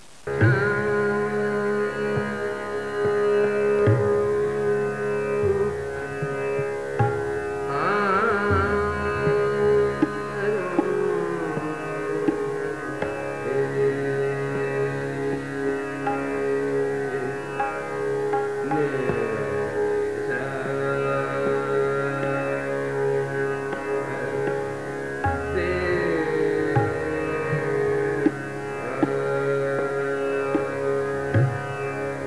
Try to see if you can hear the drone and drum in the first piece.